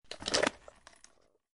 WeaponReady.wav